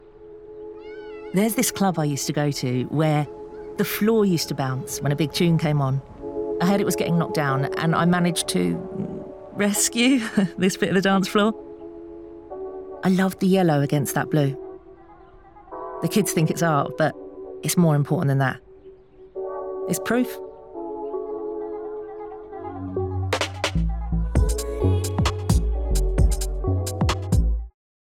Inglés (Reino Unido)
Profesional
Accesible
Provocativo